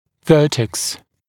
[‘vɜːteks][‘вё:тэкс]вершина, темя; самая верхняя точка темени по средней линии